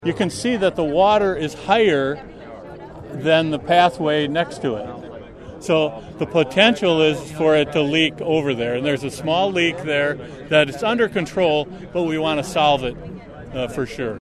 There was a big crowd for a ribbon cutting by the Illinois and Michigan Canal tollhouse for the canal re-watering project.